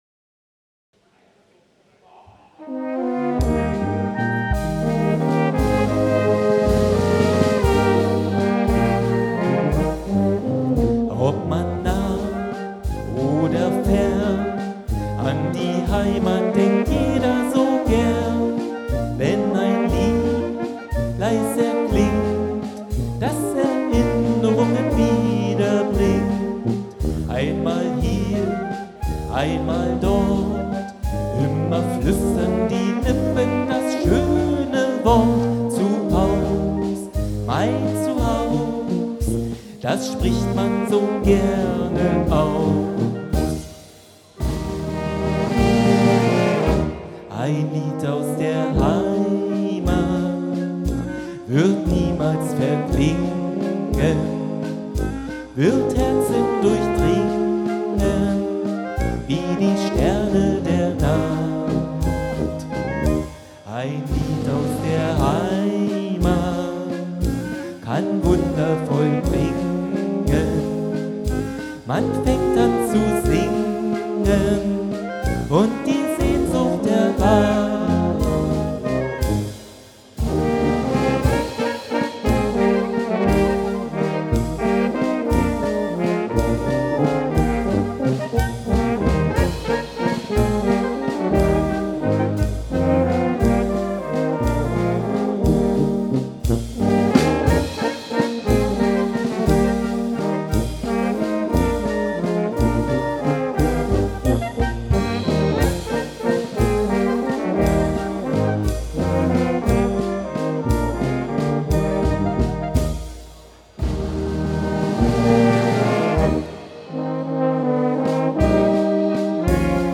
Böhmische Blasmusik aus dem Havelland